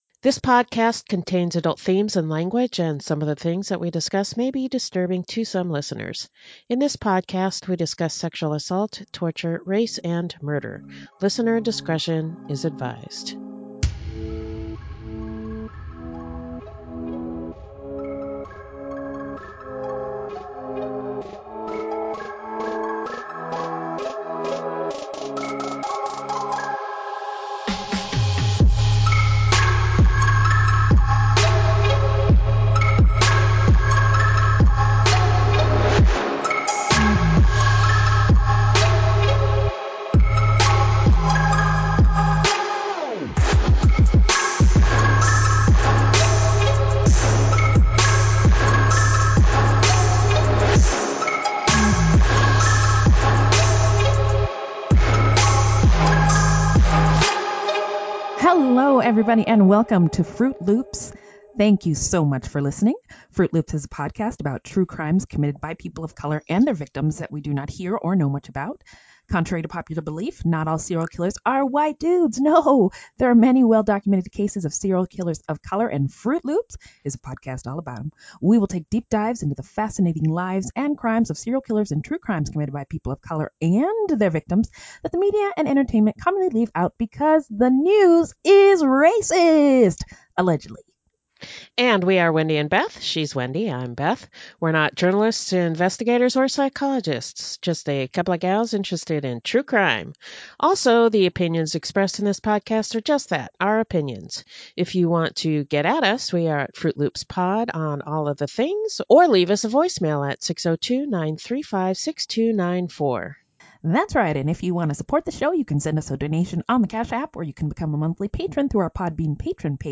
Bonus: Interview